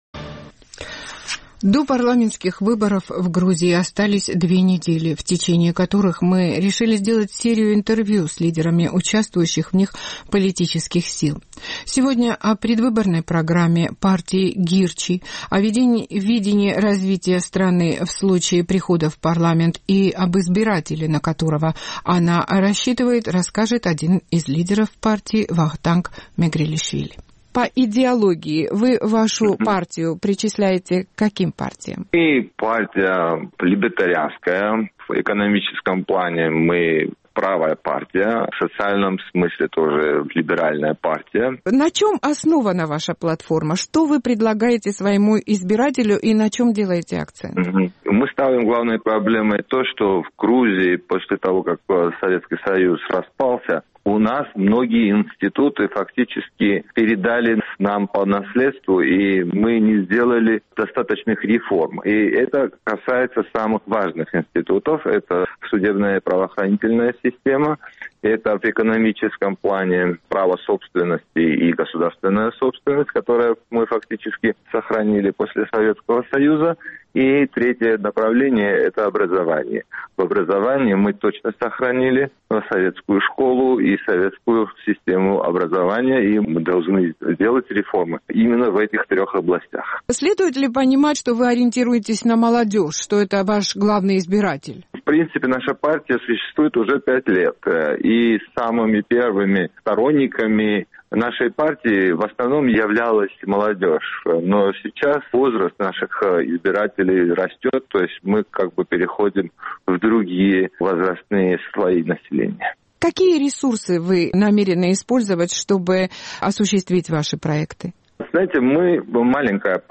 До парламентских выборов в Грузии остались две недели, в течение которых мы подготовим серию интервью с лидерами участвующих в них политических сил.